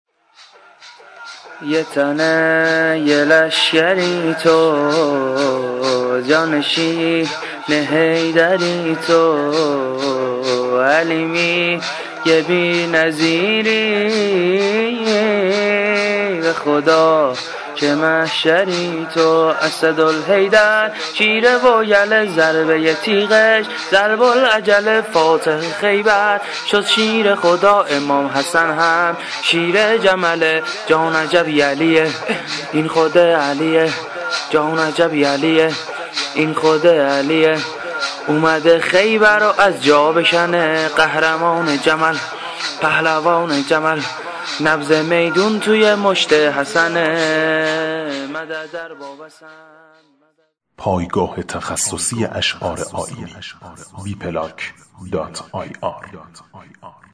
شور - - -